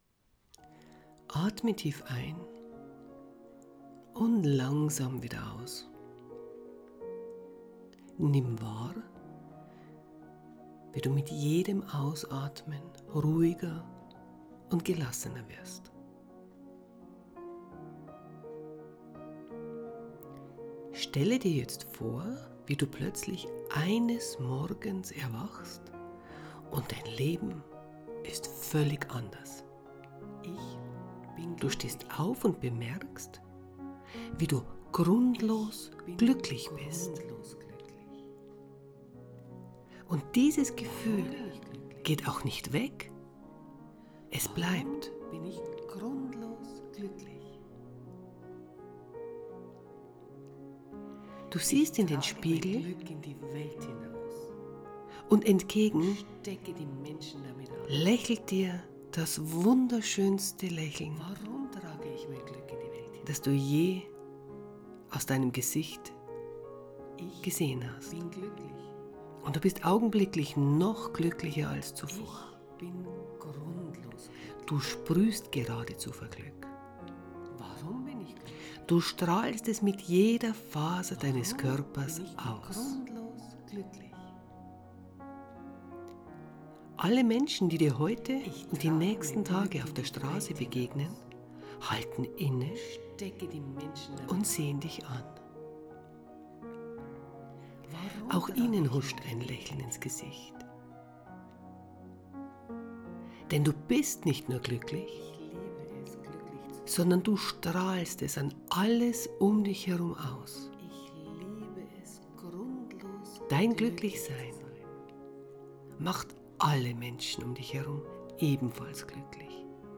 3 Minuten Meditation um das Gefühl "grundlos Glücklich" zu sein in dir zu aktivieren, zu spüren und wahr zu nehmen!